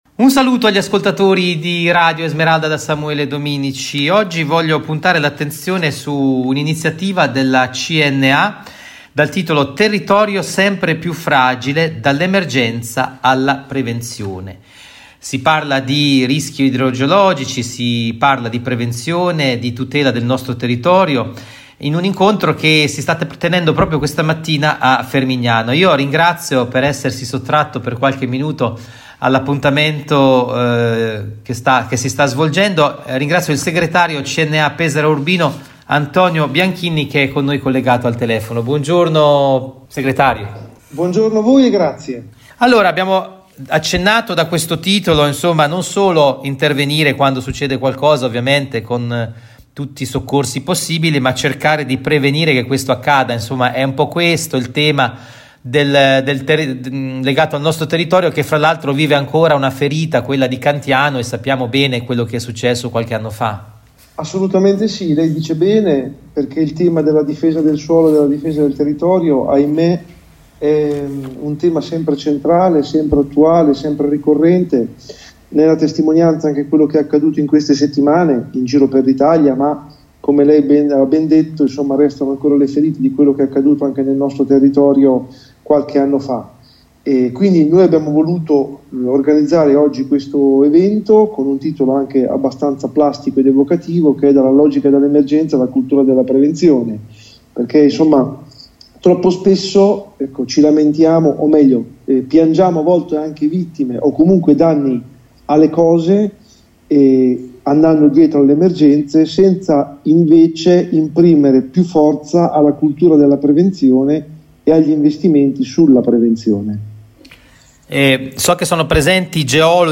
ingervista